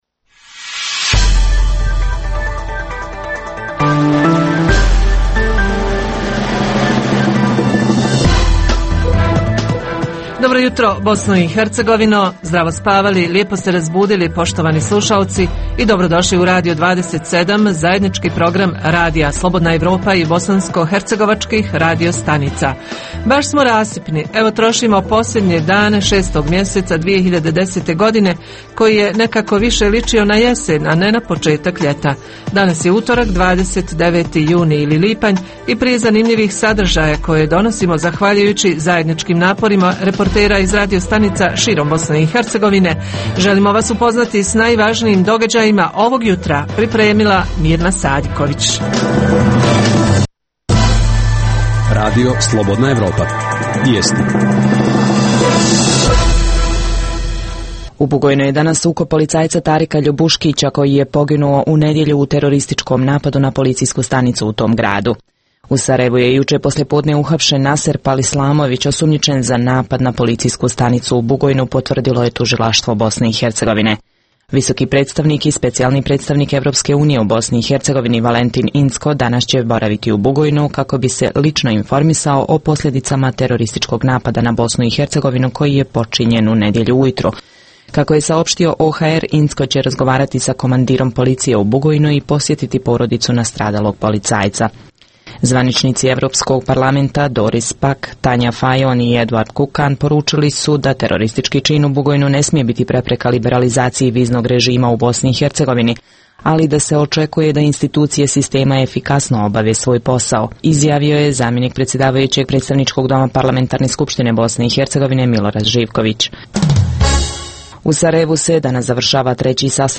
Uz tri emisije vijesti, u Jutarnjem programu reporteri nekoliko radio stanica govore o tome kako se u praksi ostvaruje projekat porodične – obiteljske medicine. Tu je i redovna rubrika o dostignućima u svijetu modernih tehnologija – konkretnije interneta. Takođe, očekujemo javljanja „sa terena“ o događajima dana u više mjesta BiH.